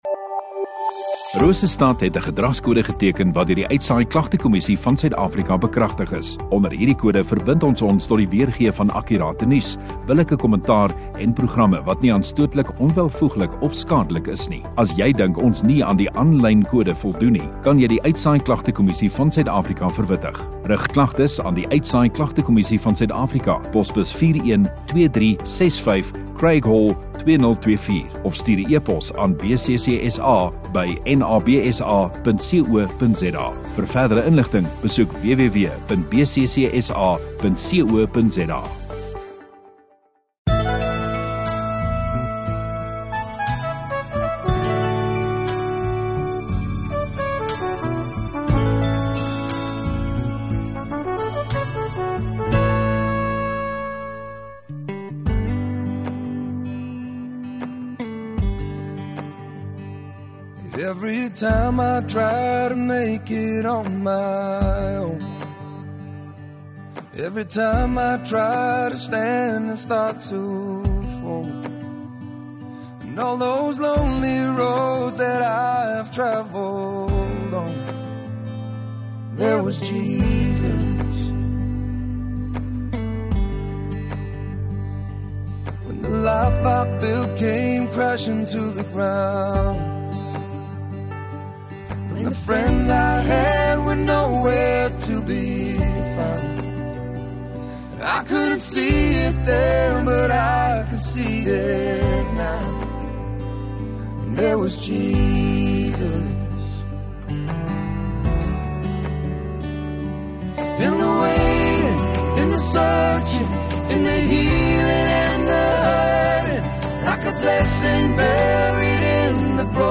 5 Jul Saterdag Oggenddiens